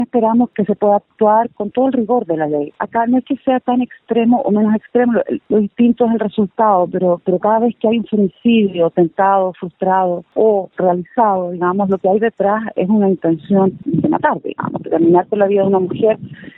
La directora regional del SernamEG, Francisca Pérez, dijo que, en estos casos, en el fondo, está la intención de matar a una mujer.